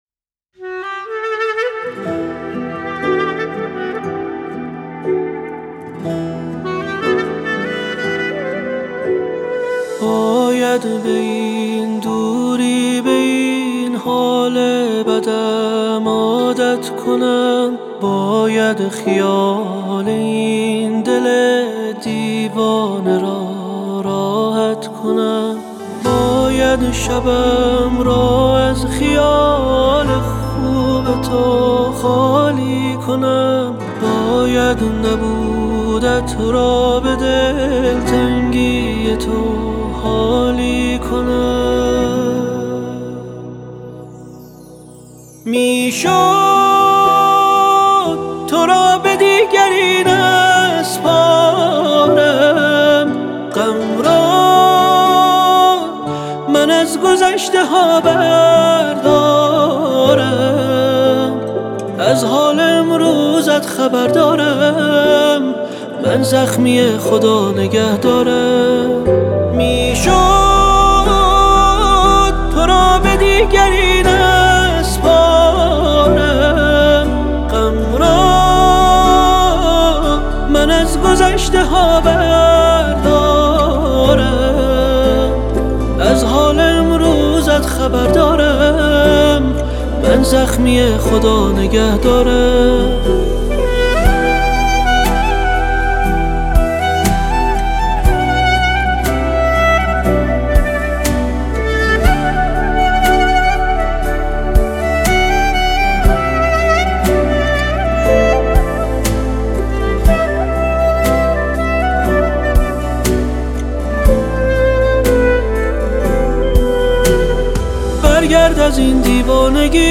صدای دلنشین و با احساس
ترکیبی از ریتم‌های جدید و پرانرژی